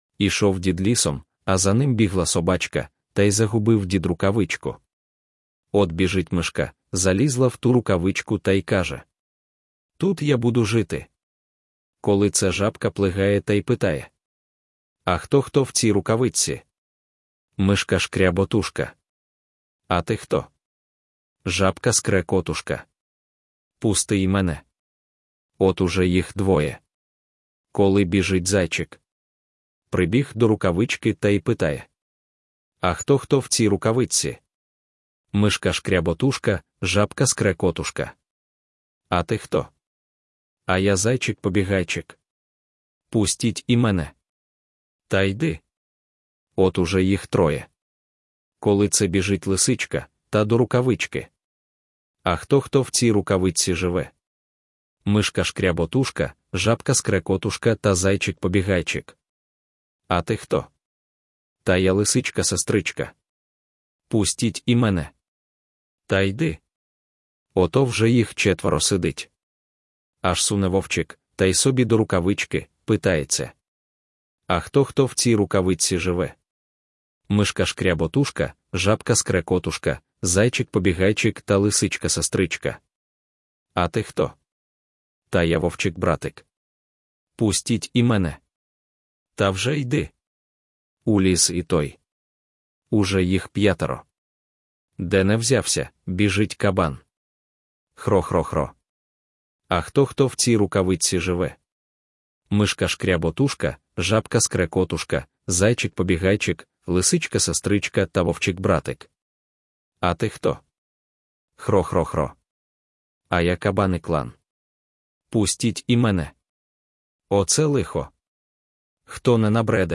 Аудіоказка Рукавичка